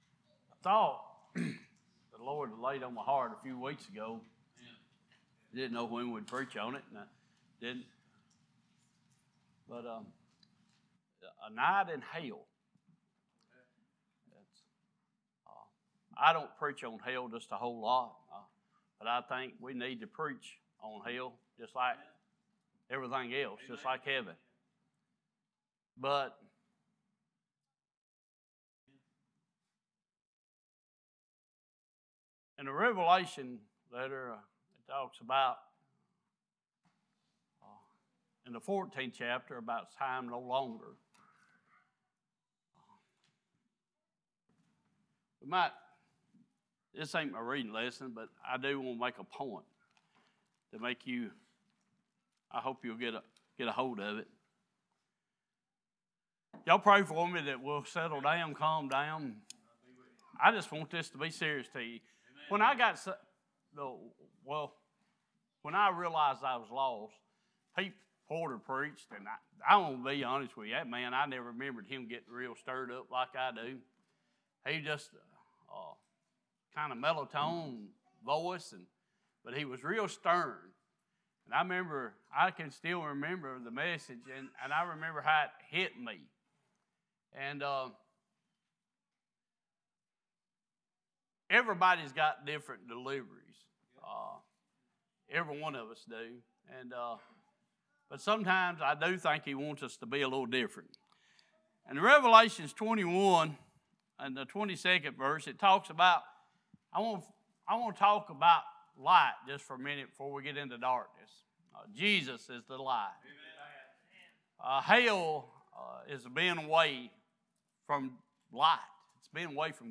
Series: Sunday Morning
2 Peter 2:4 Service Type: Worship « There Is No Rewind Button Are You Prepared For The Fire?